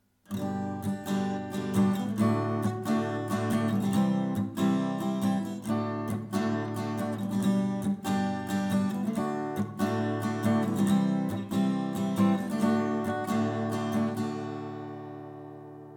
und unten kannst Du hören, wie sie mit einem einfachen Schlagmuster klingen.
I – V – IV – V (G-Dur)
I-V-IV-V-G-Dur.mp3